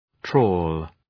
Προφορά
{trɔ:l}